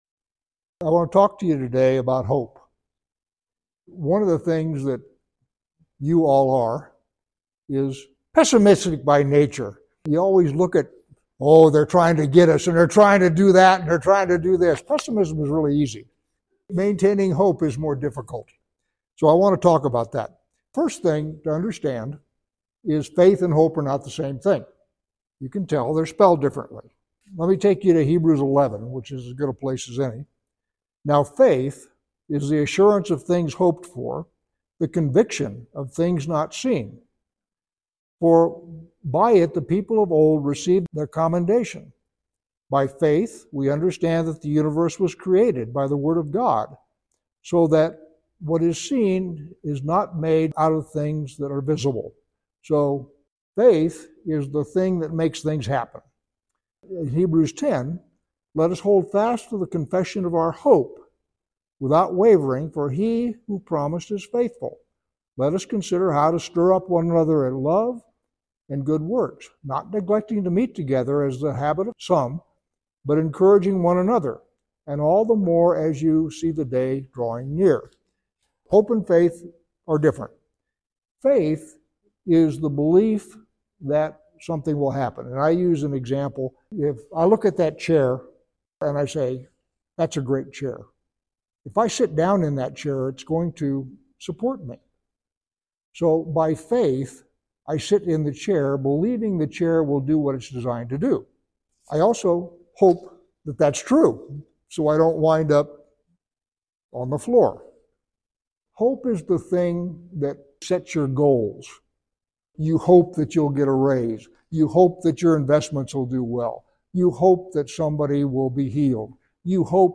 Sermons 2025 | The Crimson Thread